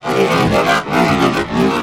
ALIEN_Communication_17_mono.wav